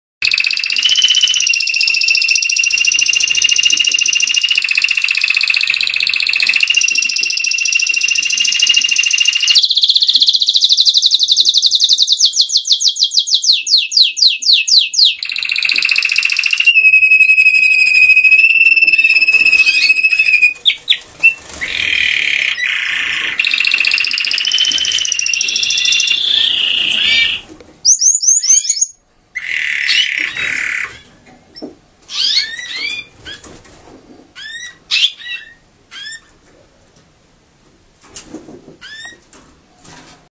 دانلود صدای قناری نر و ماده گلاستر در حال جفت گیری از ساعد نیوز با لینک مستقیم و کیفیت بالا
جلوه های صوتی